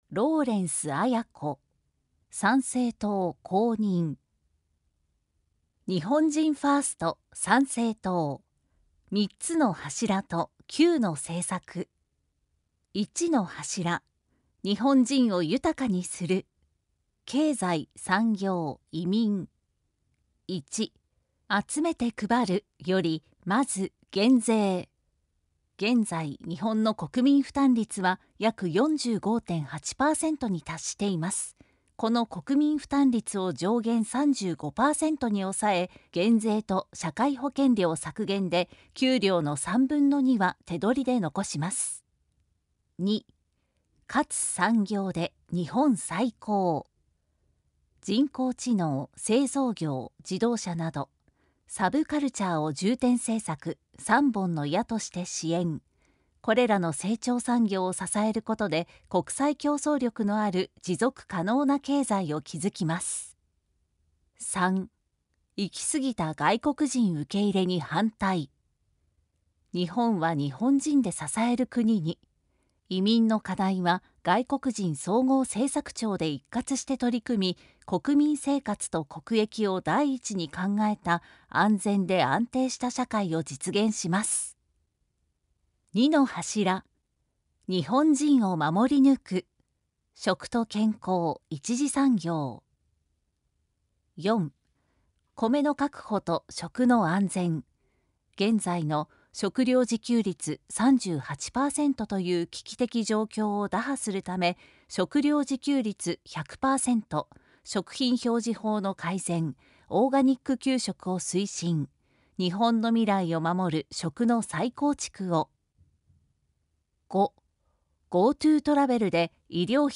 参議院議員通常選挙候補者・名簿届出政党等情報（選挙公報）（音声読み上げ用）（音声版）
選挙公報音声版（MP3：4,427KB）